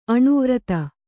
aṇu vrata